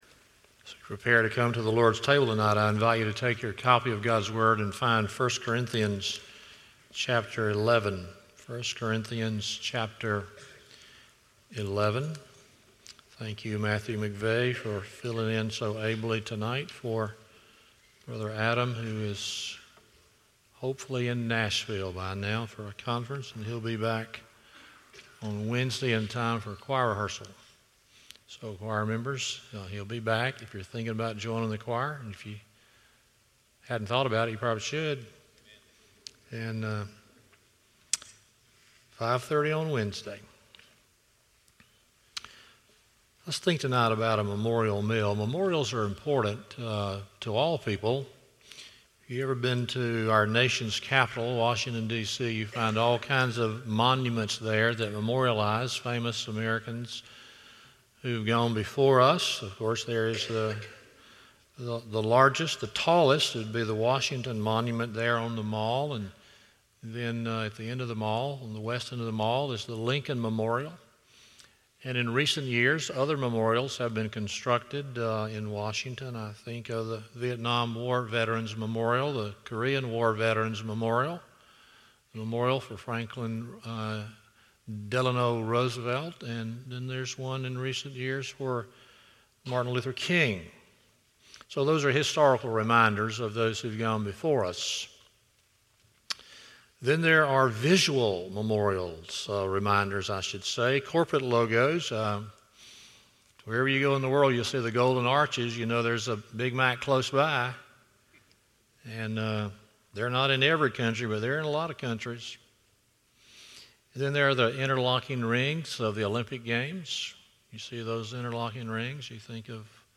1 Corinthians 11:23-32 Service Type: Sunday Evening 1.